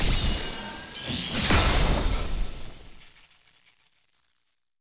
sfx-tft-skilltree-ceremony-lightning-element-enter.ogg